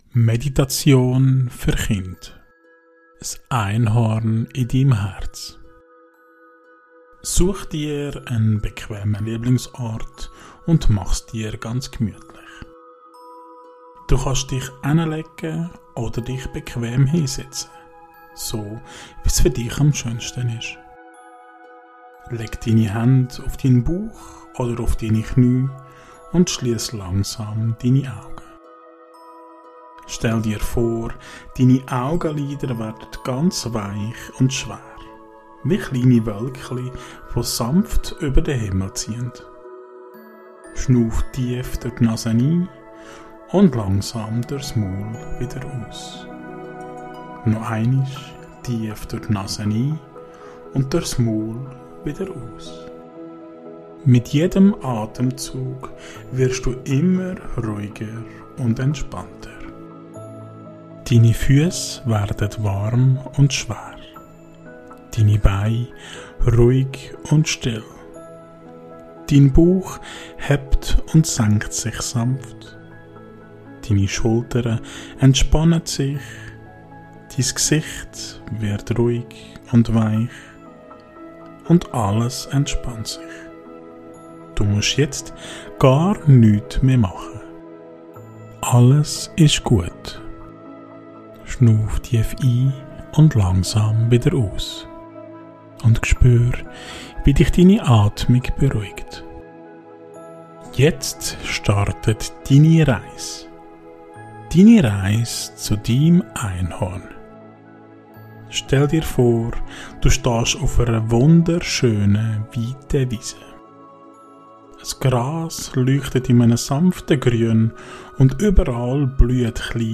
Beschreibung vor 3 Monaten Meditation für Kinder – Das Einhorn in deinem Herzen In dieser zauberhaften Fantasiereise begegnen Kinder ihrem ganz persönlichen Einhorn – einem liebevollen Begleiter, der sie an ihr inneres Licht erinnert. Gemeinsam fliegen wir über blühende Wiesen, durch Wolken aus Glitzer und Licht und entdecken im Herzen ein warmes, goldenes Leuchten.